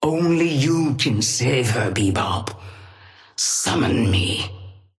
Sapphire Flame voice line - Only you can save her, Bebop. Summon me.
Patron_female_ally_bebop_start_06.mp3